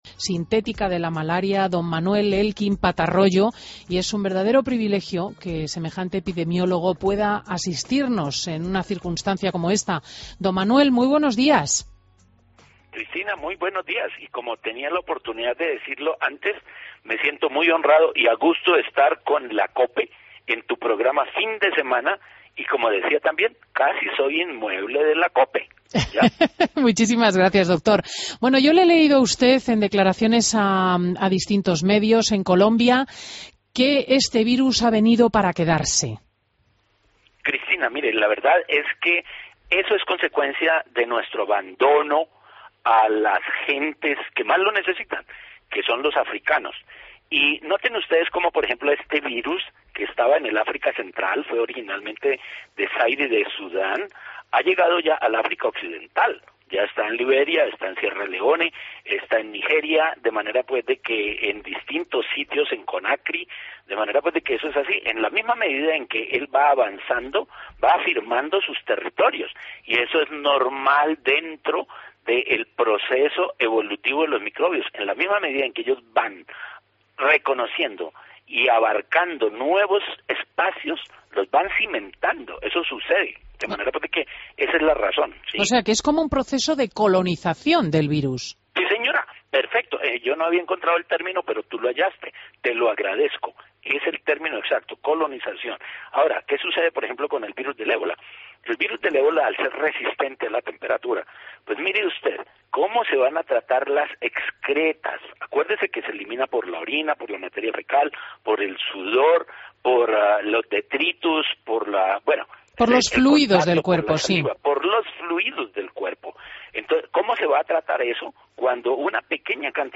Entrevista a Manuel Elkin Patarroyo en Fin de Semana COPE